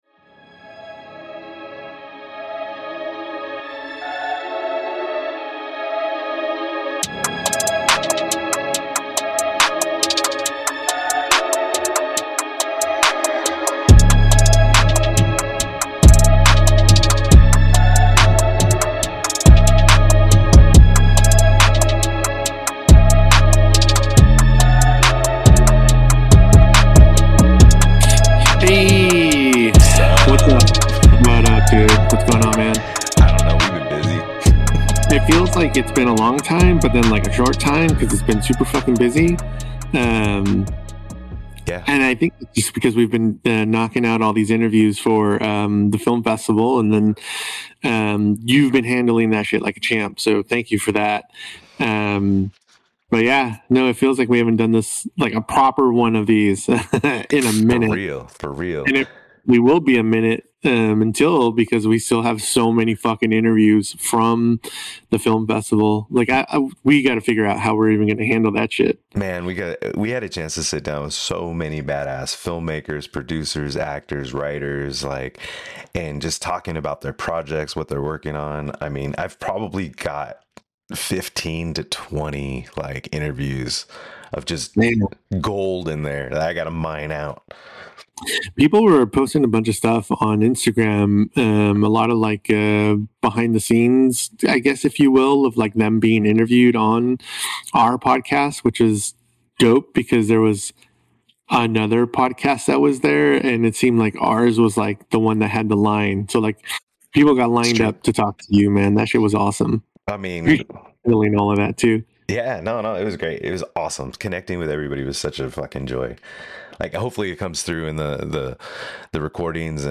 Heather Langenkamp - Interview